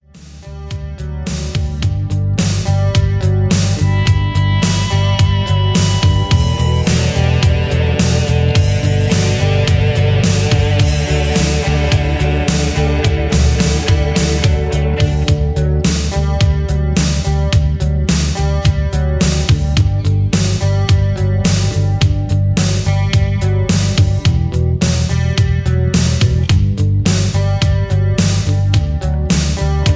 backing tracks, rock